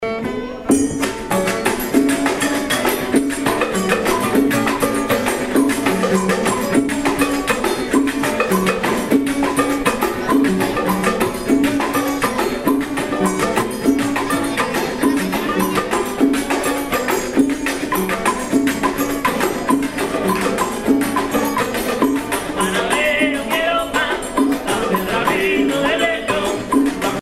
Son ou Guaracha
Note par Parranda de Los Hermanos Sobrino
Pièces musicales tirées de la Parranda Tipica Espirituana, Sancti Spiritus, Cuba
Pièce musicale inédite